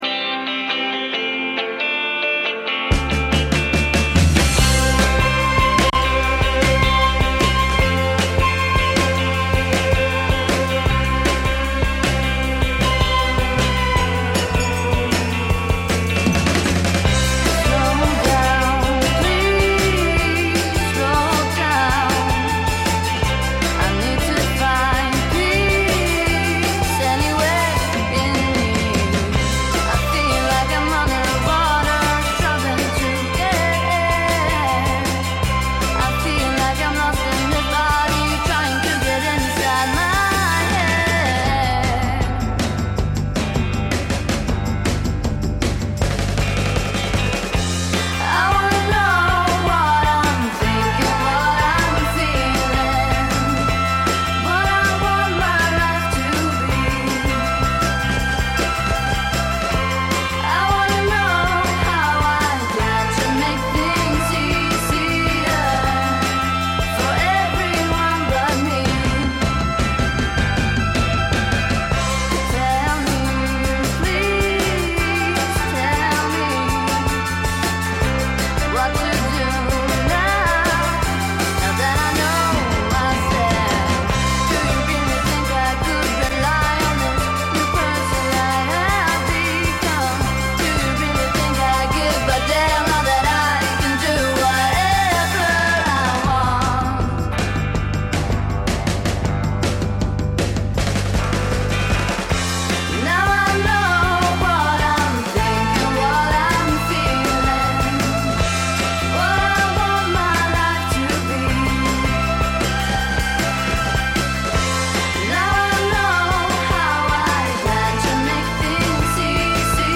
F.R.E.I.sprecher jeden ersten Mittwoch im Monat von 17.00 bis 18.00 Uhr Wiederholung am darauf folgenden Sonntag zwischen 12.00 und 13.00 Uhr Die Sendung wird von Kindern zwischen 9 und 13 Jahren produziert. Was gesendet wird, bestimmen sie selbst: ob Lieblingsband, Computerspiele, Freizeittipps oder philosophische Welterkl�rungen. Ihre Ideen verarbeiten die Kinder in Umfragen, Interviews, Reportagen oder H�rspiele.